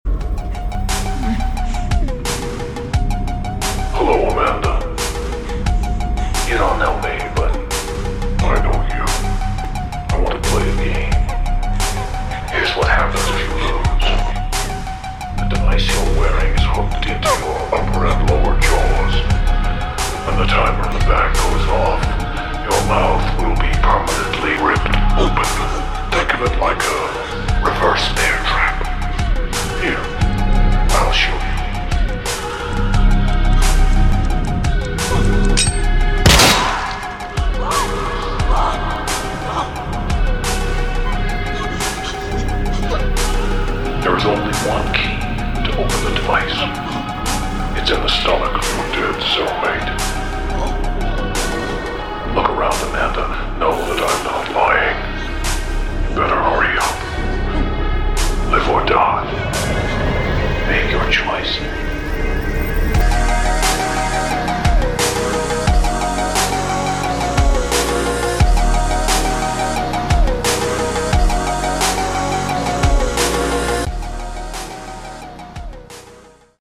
One Of The Greatest Horror Sound Effects Free Download